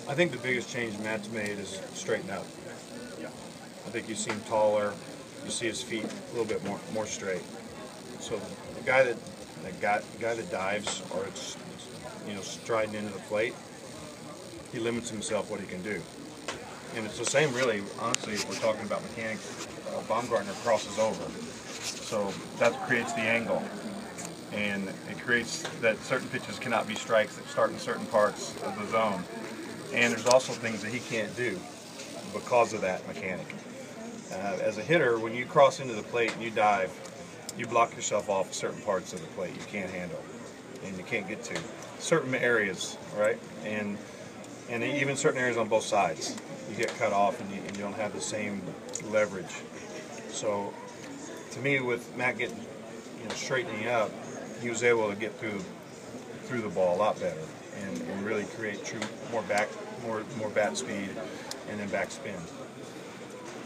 Much of this has been attributed to Kemp’s improved health, but as Don Mattingly discussed today (audio above), an adjustment to Kemp’s mechanics has also been a factor.